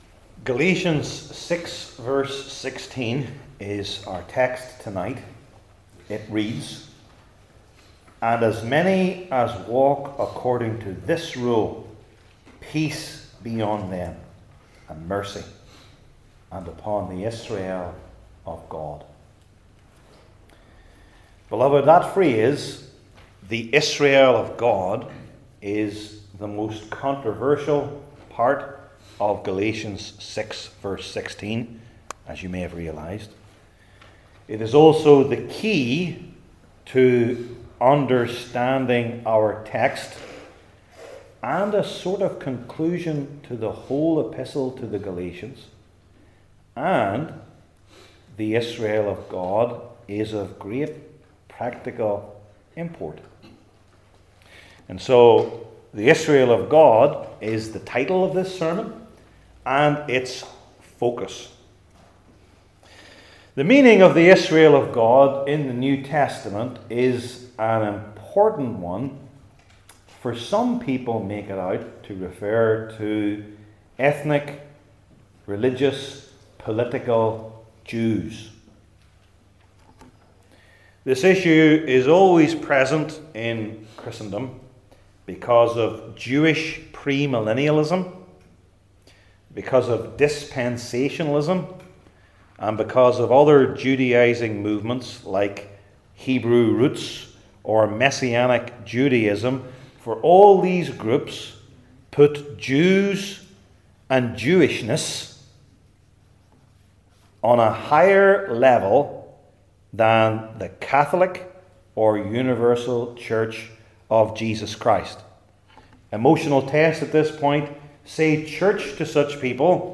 New Testament Individual Sermons I. Who They Are Not II.